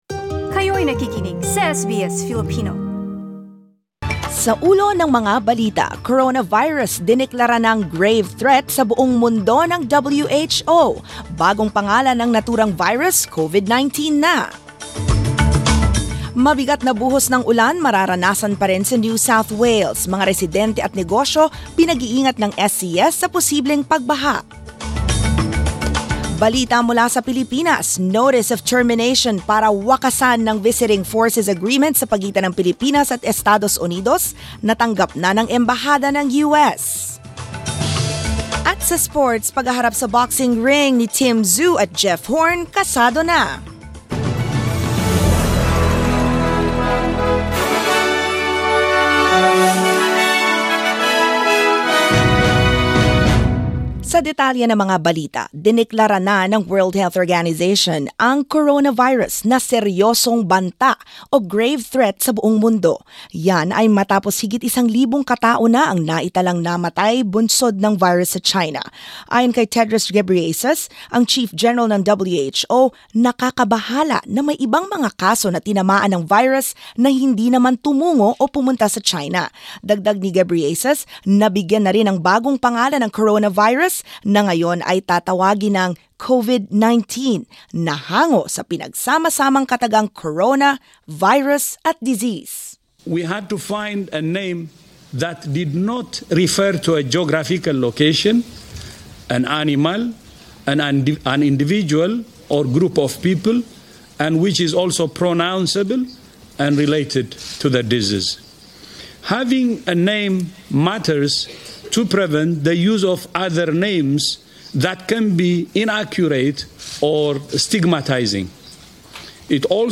SBS News in Filipino, Wednesday 12 February